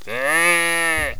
sheep4.wav